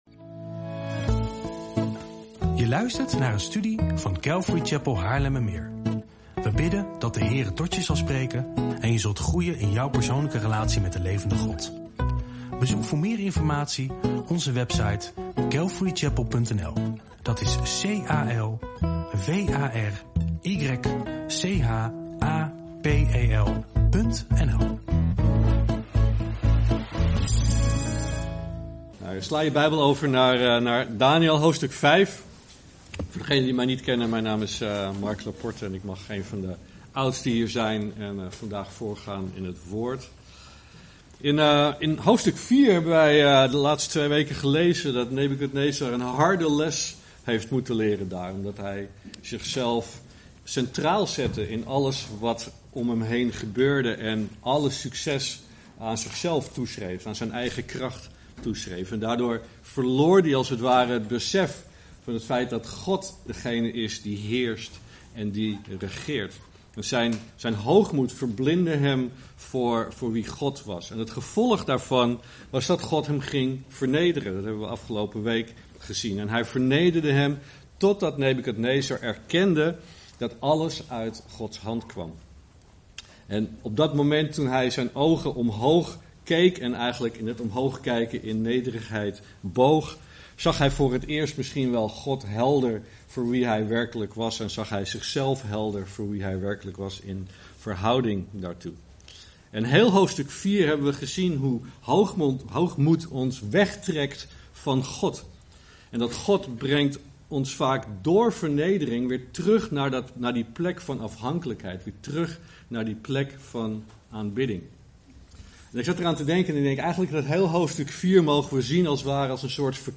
Bijbelstudie en Uitleg - Hoogmoed doet God ons oordelen